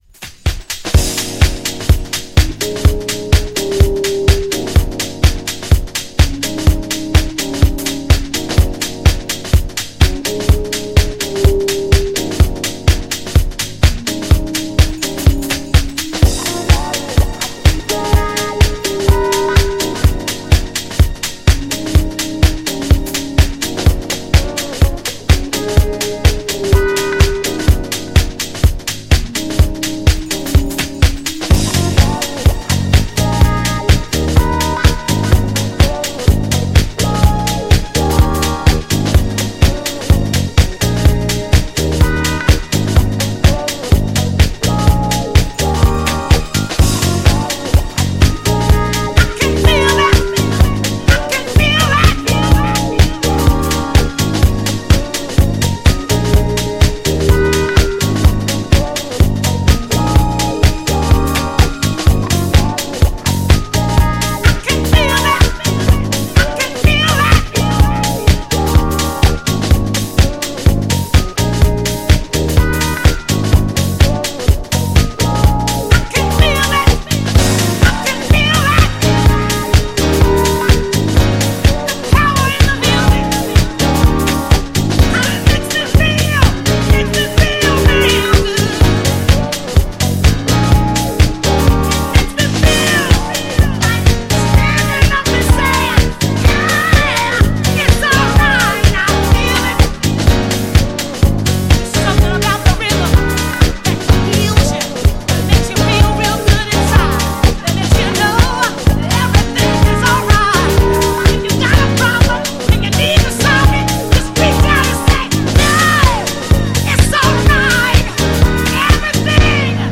GENRE House
BPM 121〜125BPM
熱いボーカル